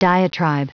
Prononciation du mot diatribe en anglais (fichier audio)
Prononciation du mot : diatribe